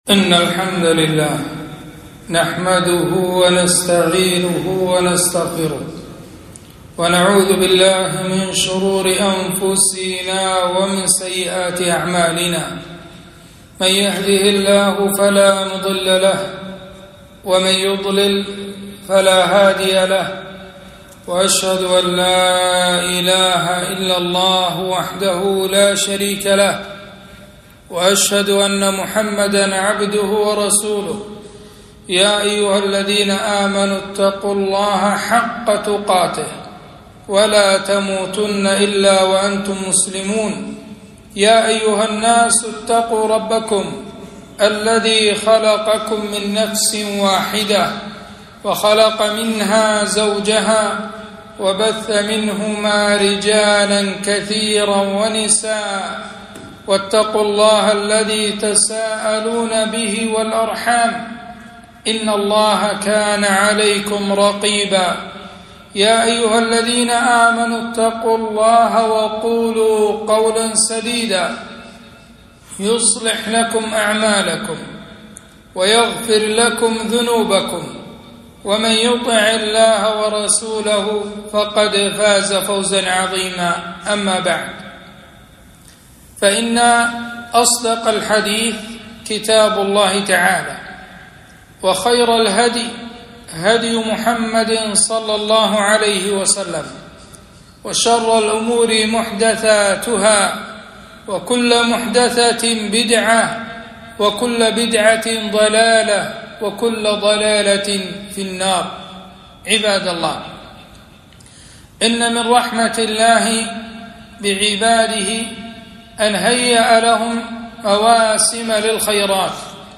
خطبة - أحكام الأضحية وفضائلها